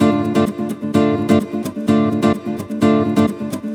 VEH2 Nylon Guitar Kit 128BPM